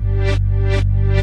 DreChron ReStringHit.wav